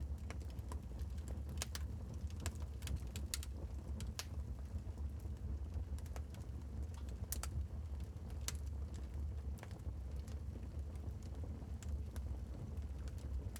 sfx_fireplacewinter_loop.wav